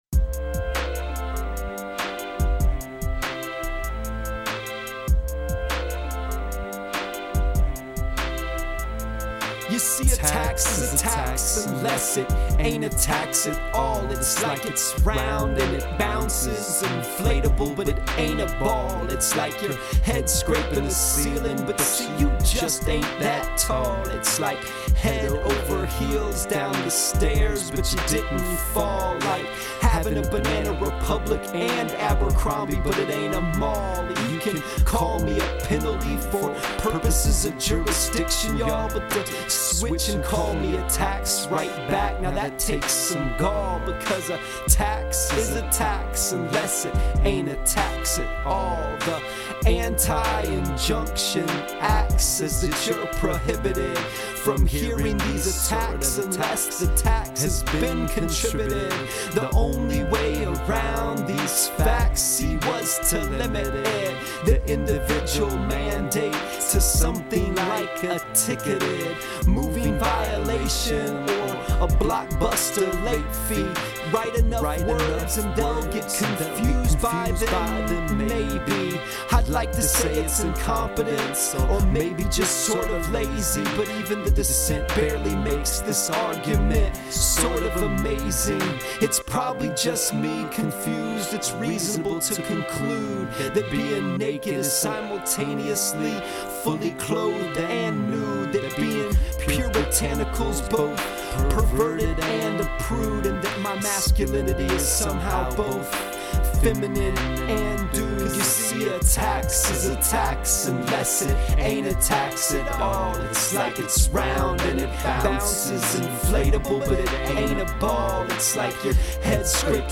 Today’s song blog here: